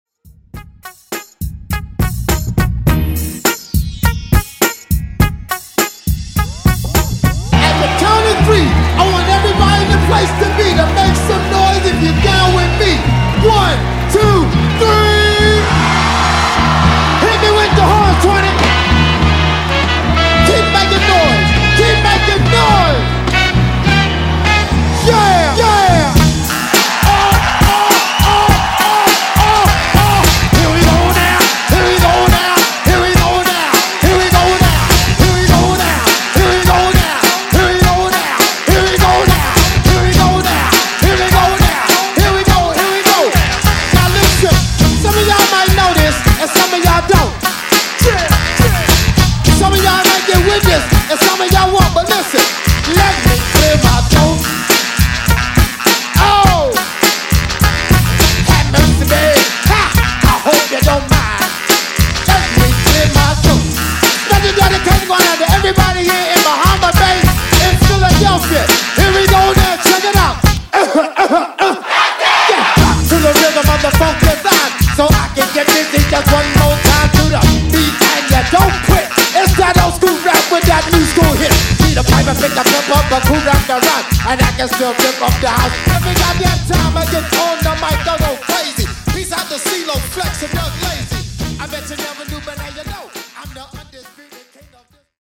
Vocal Blend Old School)Date Added